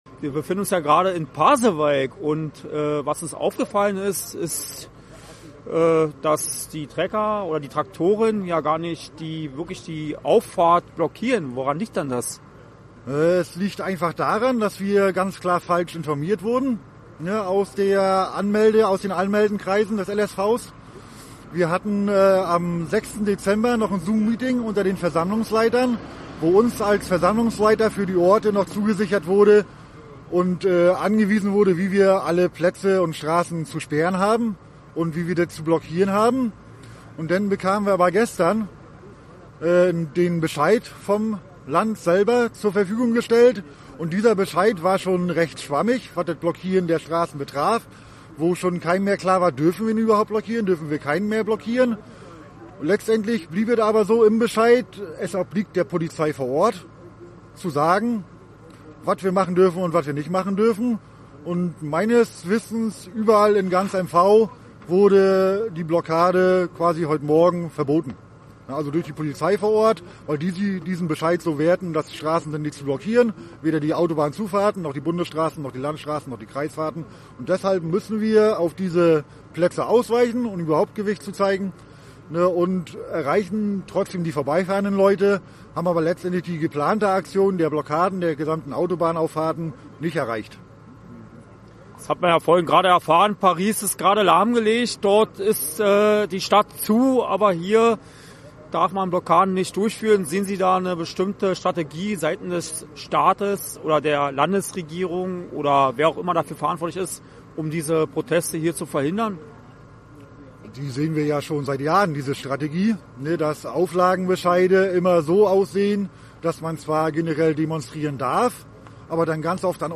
im Gespräch mit dem Landwirt